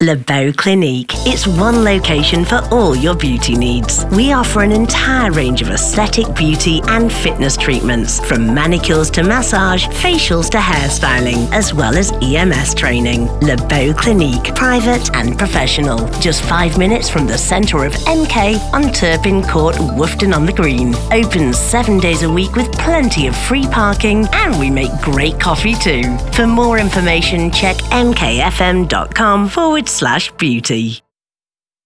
Naturelle, Accessible, Chaude, Corporative, Fiable
Guide audio
Fluide, Naturelle, Riche, Profonde, Sensuelle, Rauque, Nordique, Mancunienne, Manchester, Régionale, Chaleureuse, Assurée, Fiable, Maternisante, Rassurante, Amicale, Accessible, Conversante, Professionnelle, Dynamique, Experte.